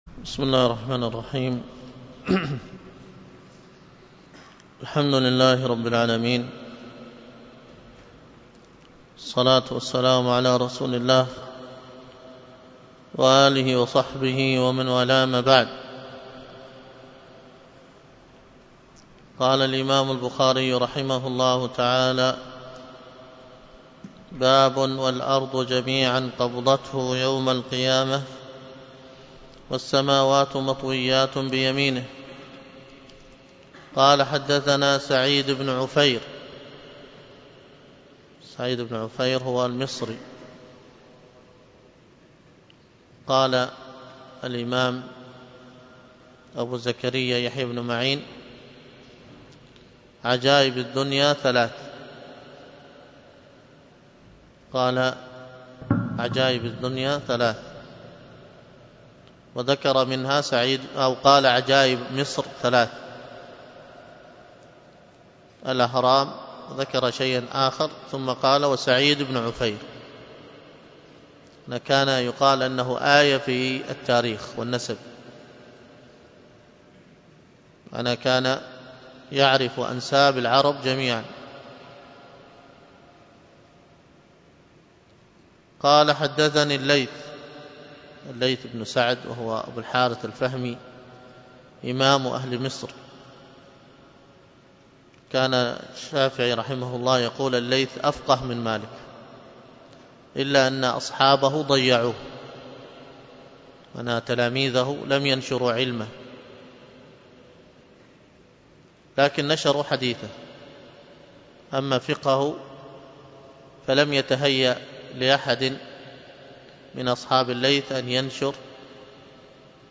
الدرس في كتاب الطهارة 23، ألقاها